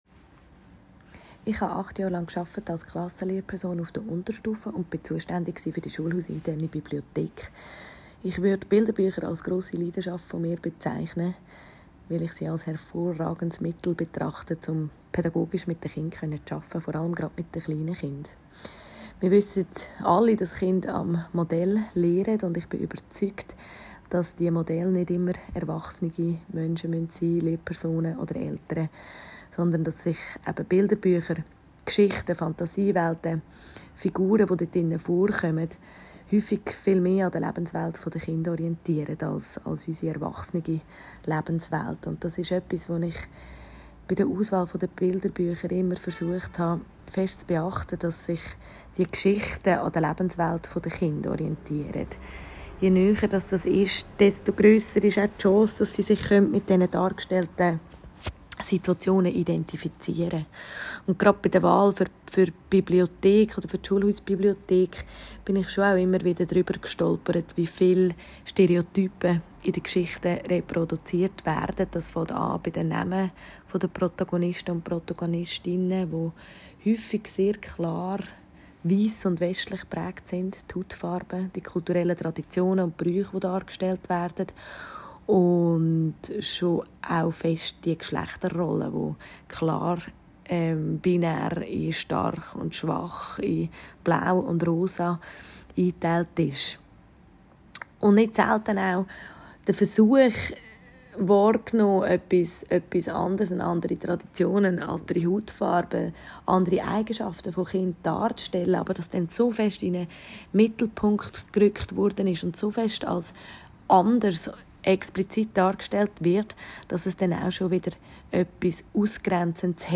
Hier hören Sie von zwei Lehrpersonen, was ihnen bei der Bücherauswahl wichtig ist: